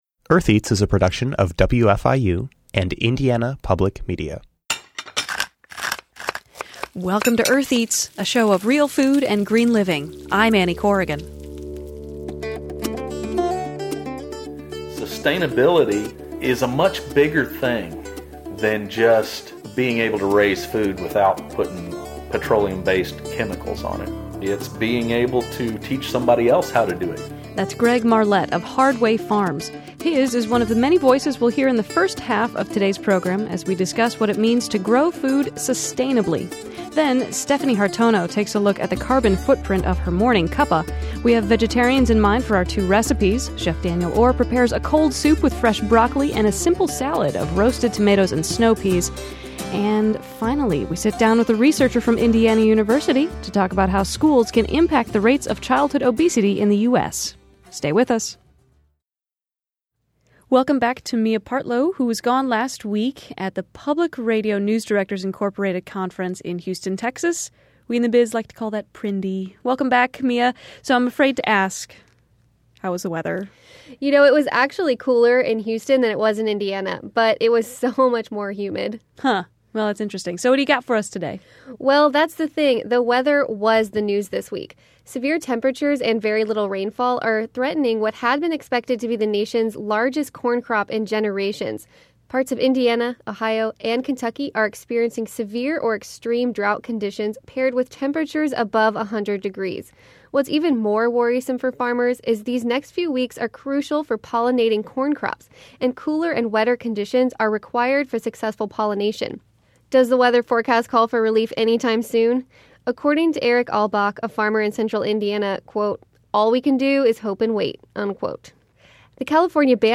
In today's show, we speak with a number of farmers and producers to find out what sustainability means to them. Then, two recipes using broccoli and snow peas.